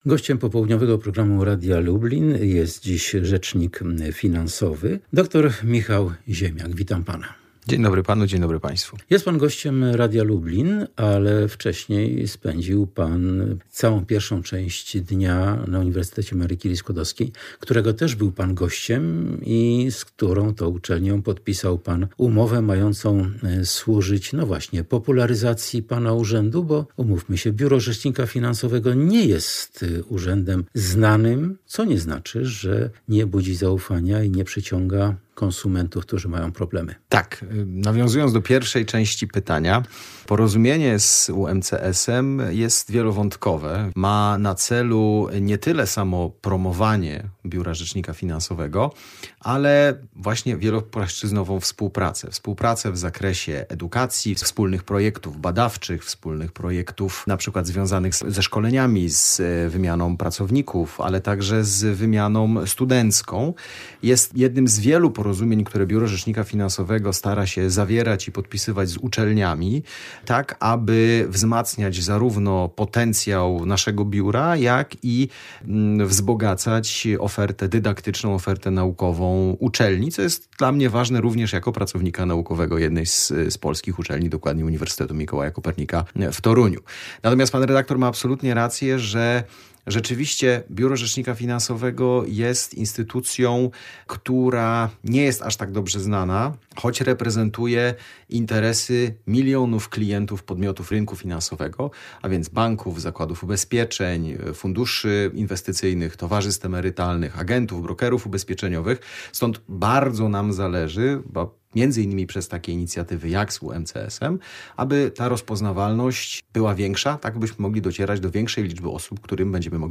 A sam Rzecznik Finansowy dr Michał Ziemiak jest gościem Radia Lublin.